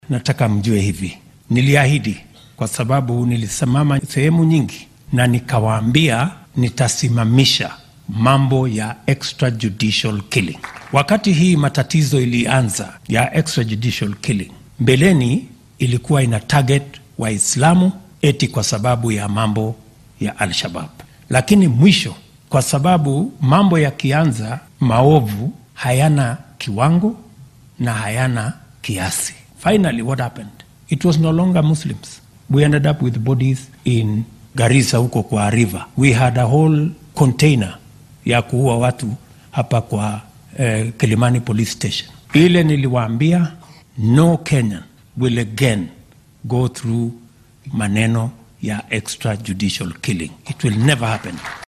Arrintan ayuu ka hadlay xilli qaar ka mid ah muslimiinta wadanka ku nool uu ku casuumay munaasabad afur ah oo shalay maqribkii lagu qabtay xarunta madaxtooyada State House ee magaalada Nairobi.